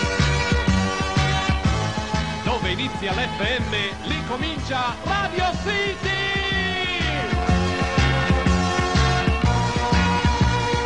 sono molto semplici ed accattivanti